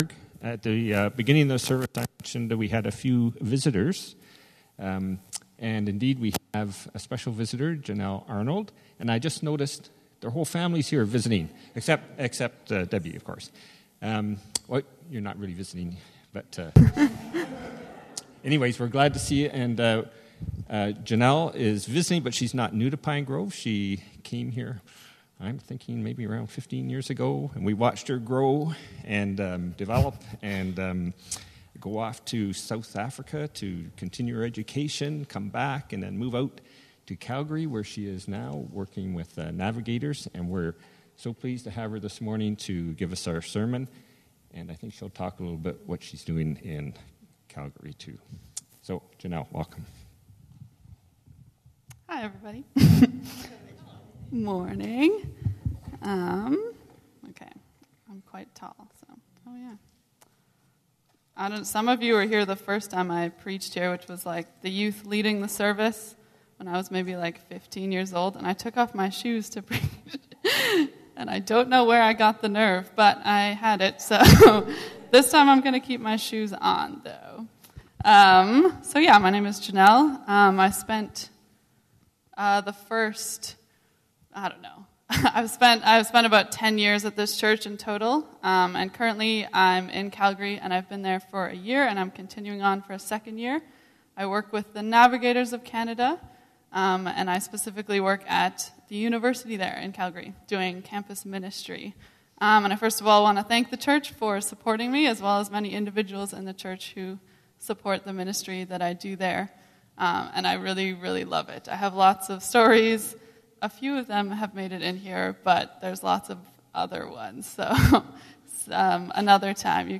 PG Sermons August 12